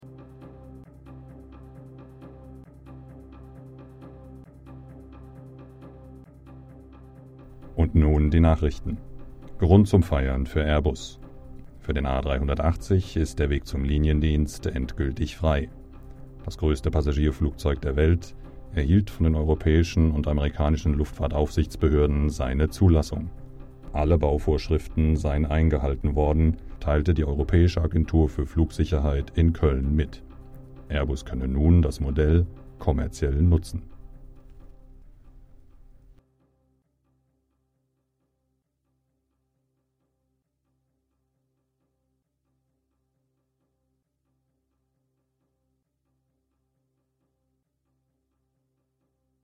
deutscher Sprecher
Sprechprobe: Sonstiges (Muttersprache):
german voice over talent